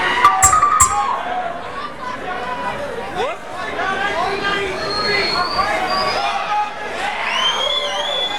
location Phoenix, Arizona, USA venue Old Brickhouse Grill